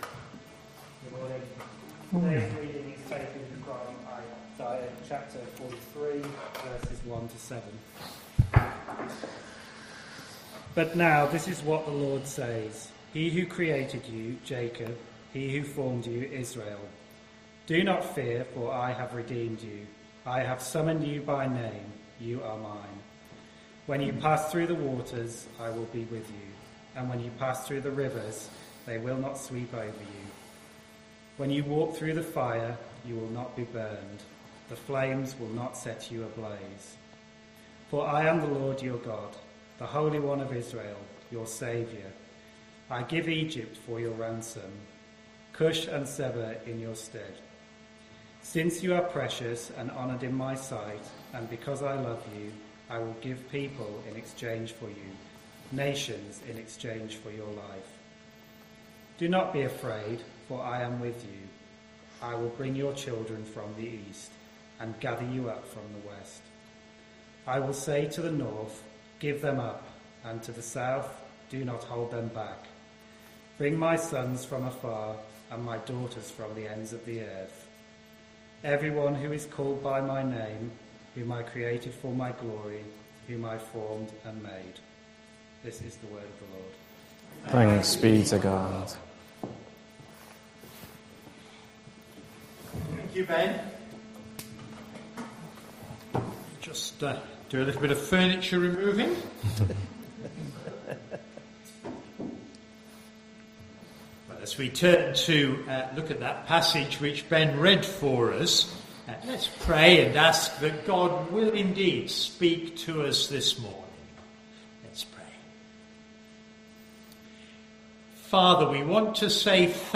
21st September 2025 Sunday Reading and Talk - St Luke's
Apologies for the poor sound quality.